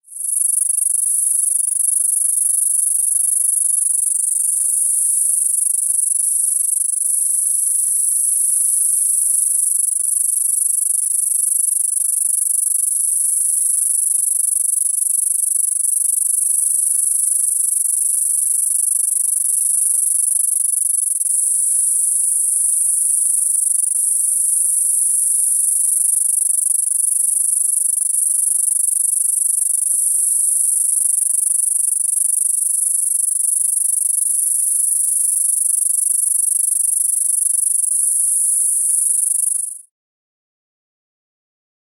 Species: Mogannia saucia